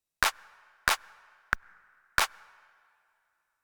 Here you will find rhythms with various types of notes in the bar.
One quarter note, one half note, and one quarter note.